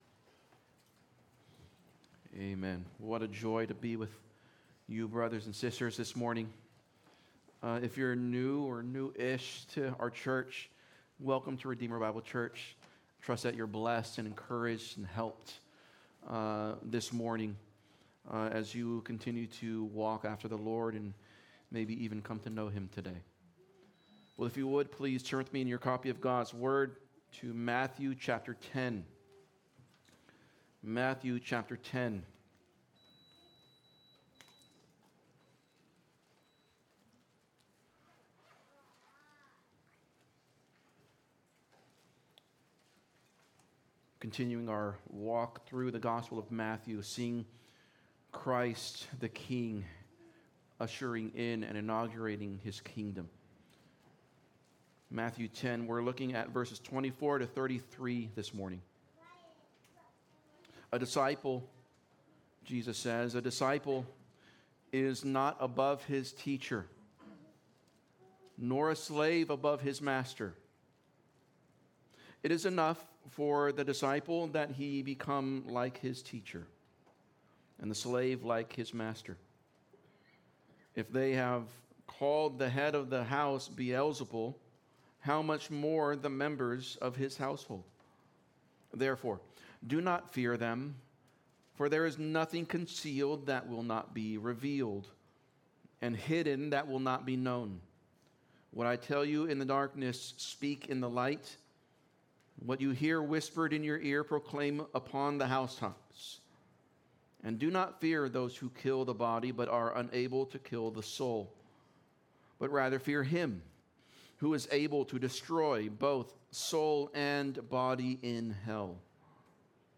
Sermon Info: Title: : The Disciple’s Fear and Faith Series: Matthew Passage: Matthew 10:24-33 Outline: 1. The Fear of Man Condemned 2. The Fear of God Commanded